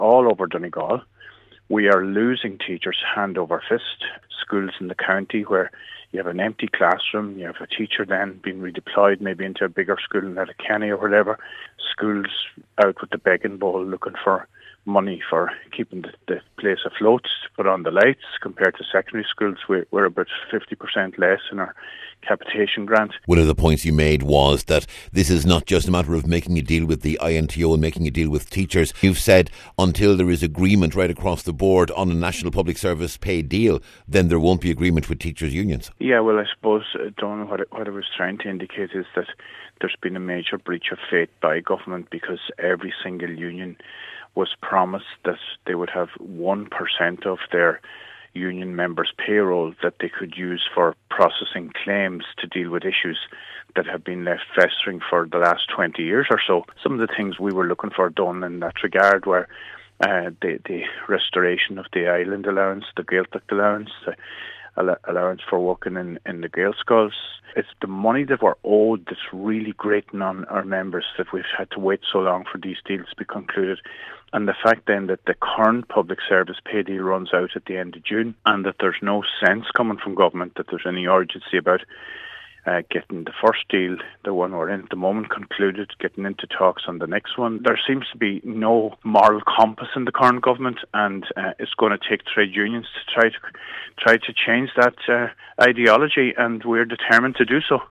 Speaking to Highland Radio News from the conference this morning, he said promises regarding long standing issues such as class sizes have not been honoured, and several pledges regarding pay and funding are still outstanding, months after they were to have been paid.